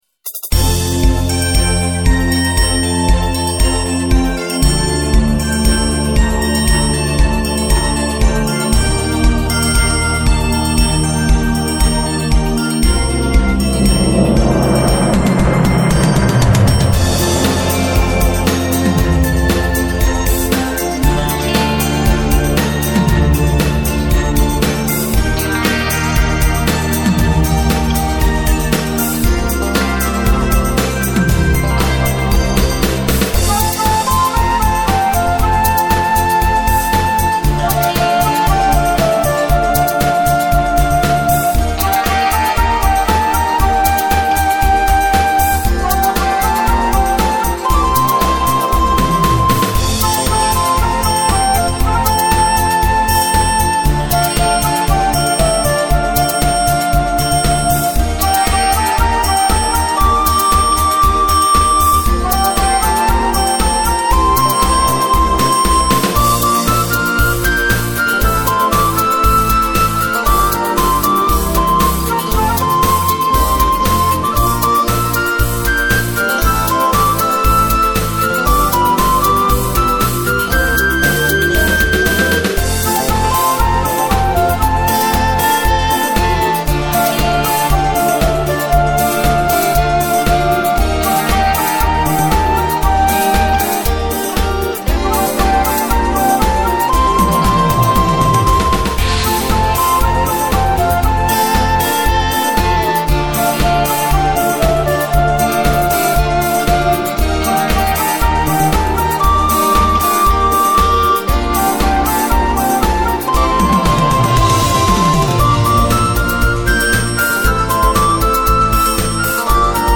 Disco-Médium
L'exemple mélodique